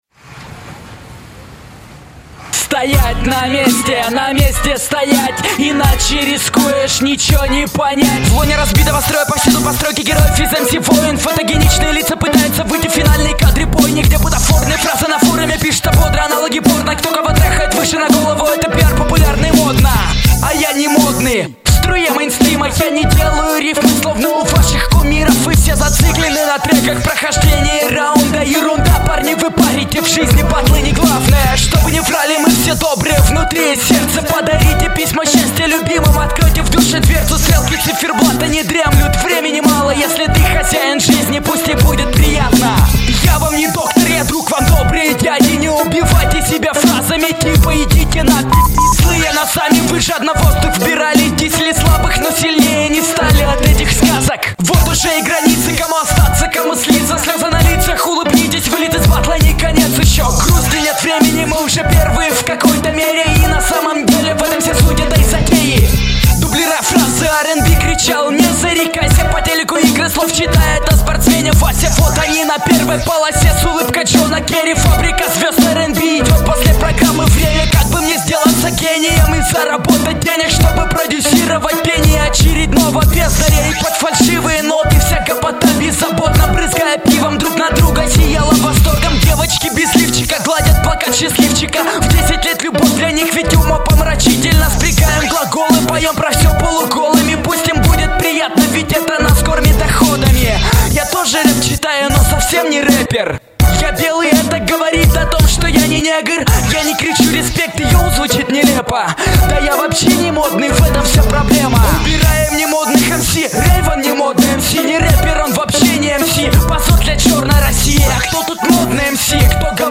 • Жанр: Рэп